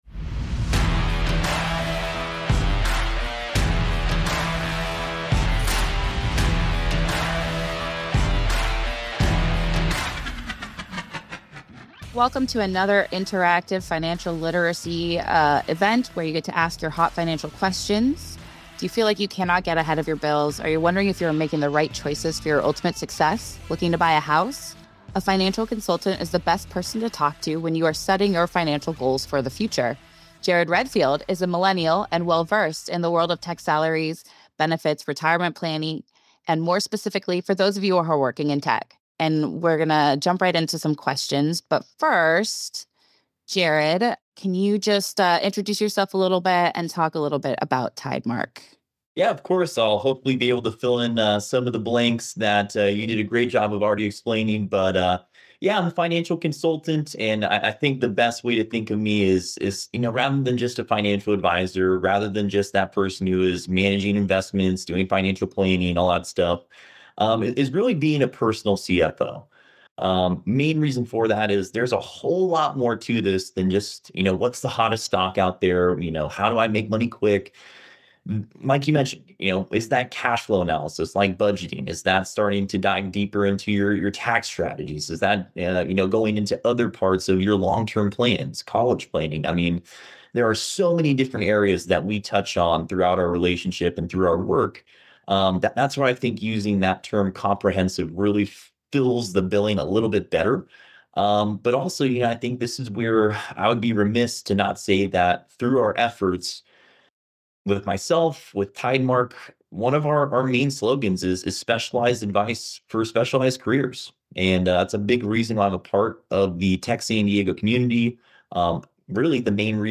TSD Spotlight is recorded remotely and edited by Hypable Impact If you enjoyed this podcast episode, make sure to like, follow and share!